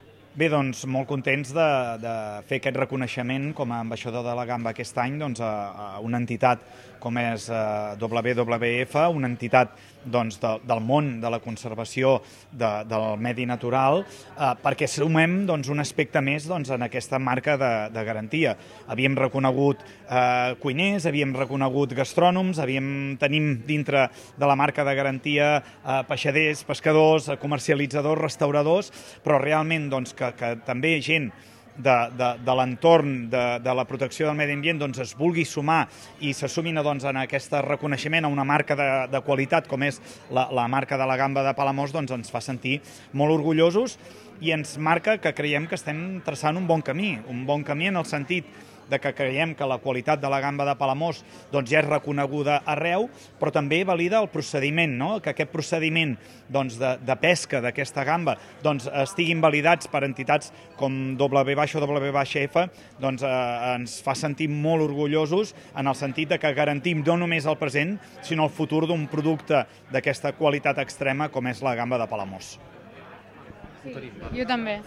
La Marca de Garantia assegura que la gamba arriba al consumidor amb el màxim nivell de qualitat, tal com apunta l’alcalde del municipi, Lluís Puig.